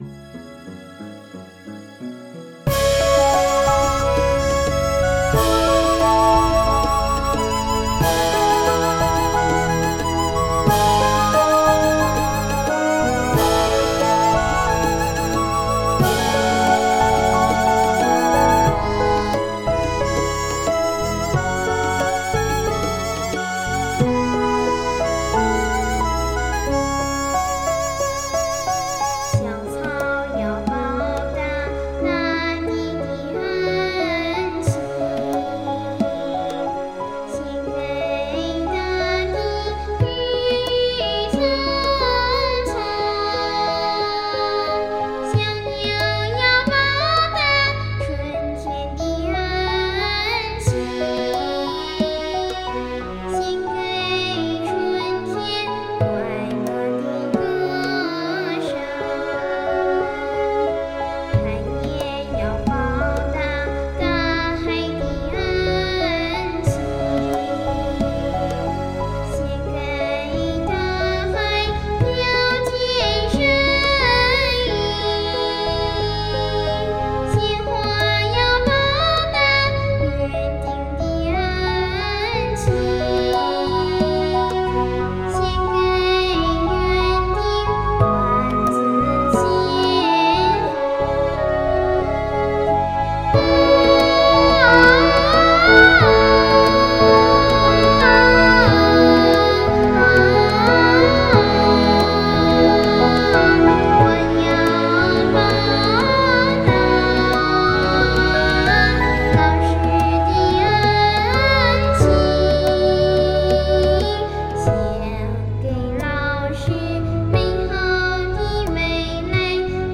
曲风：流行